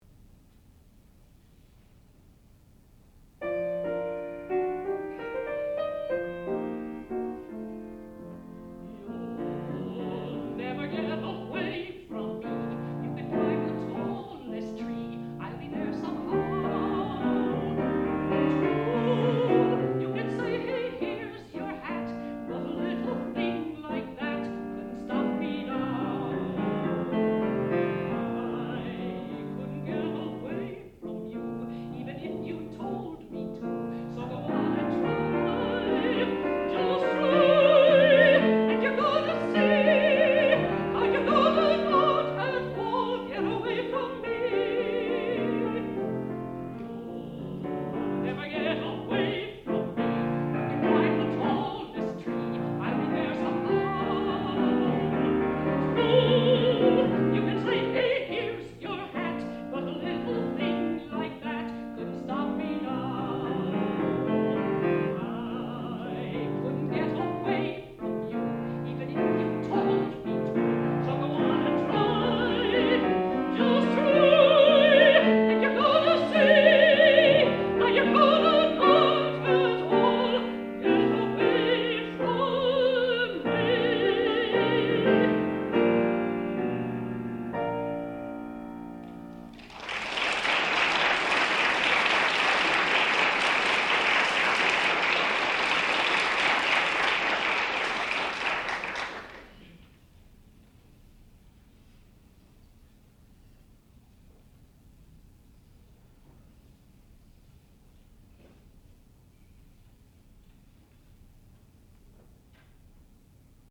sound recording-musical
classical music
piano and Frances Bible, mezzo-soprano